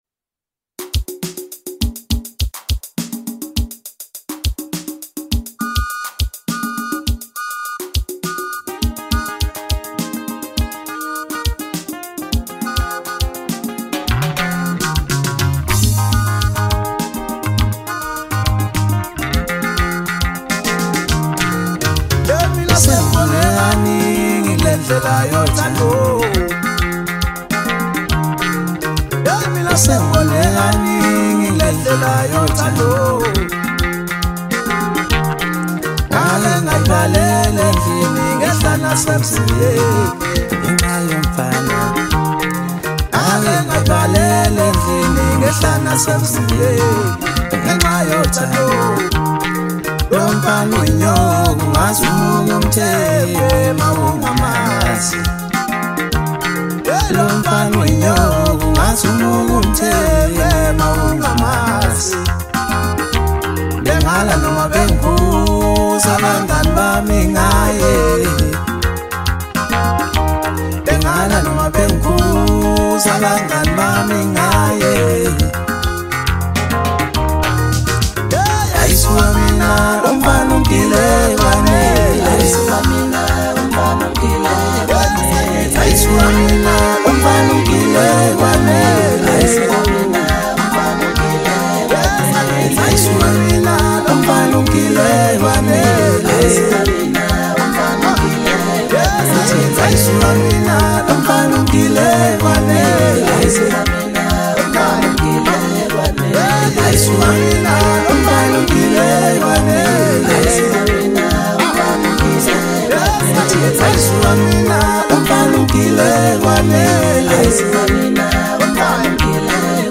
Home » Maskandi » DJ Mix » Hip Hop
South African singer-songwriter